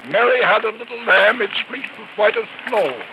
People usually talk pretty fast, and a lot of the words get blended together and pronounced very differently than if you were saying one word on its own. Listen to this recording and see if you can tell what word it is.